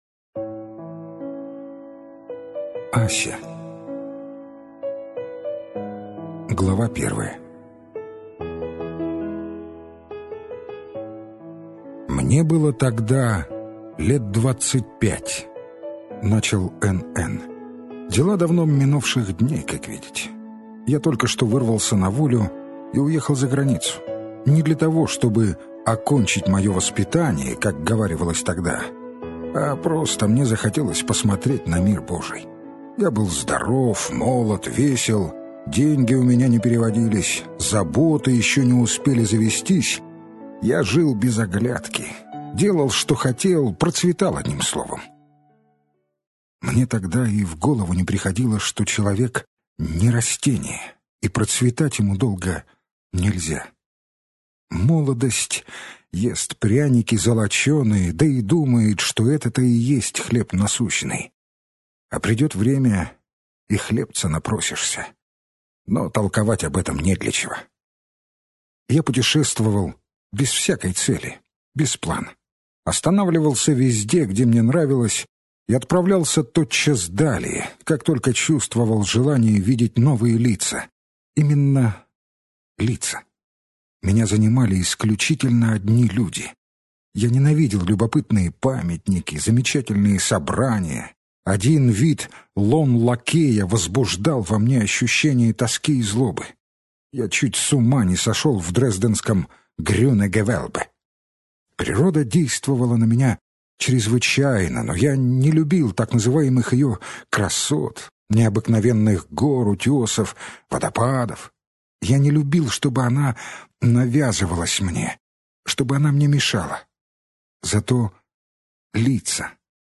Аудиокнига Записки охотника. Муму. Ася. Первая любовь | Библиотека аудиокниг